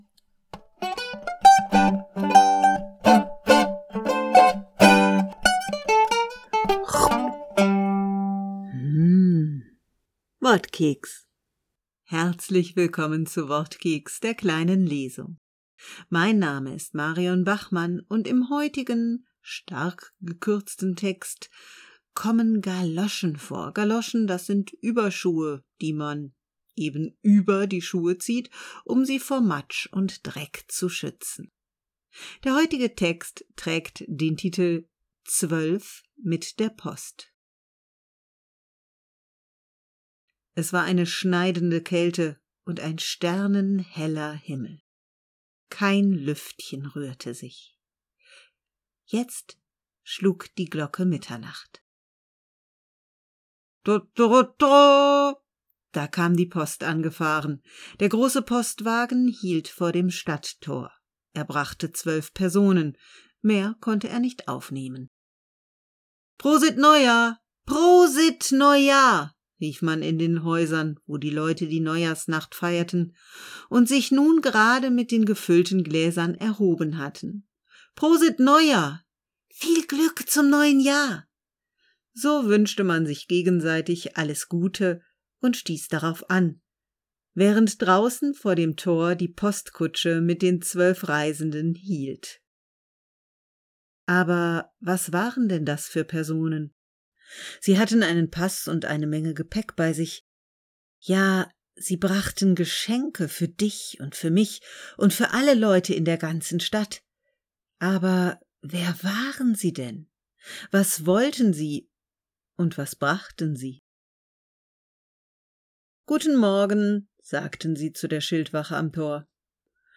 Folge 82 - Neujahrs-Zwölferkeks ~ Wortkeks - die kleine Lesung Podcast